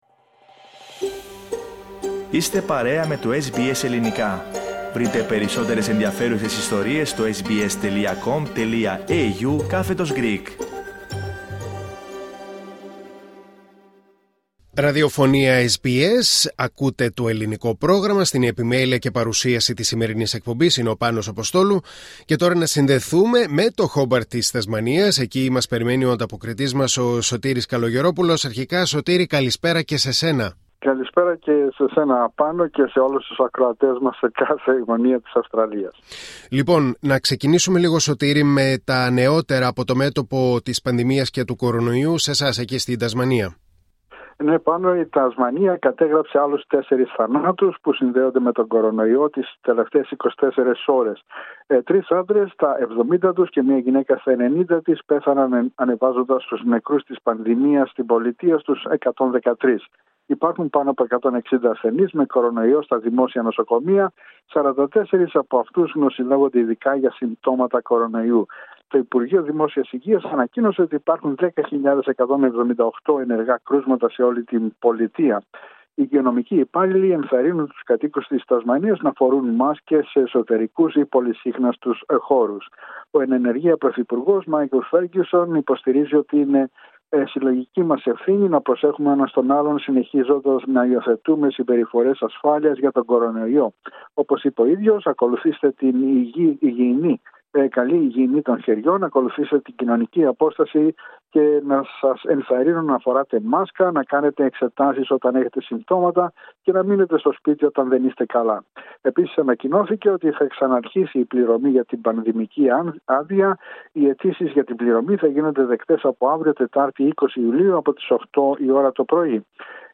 Η εβδομαδιαία ανταπόκριση από το Χόμπαρτ της Τασμανίας.